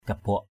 /ka-bʊaʔ/ 1.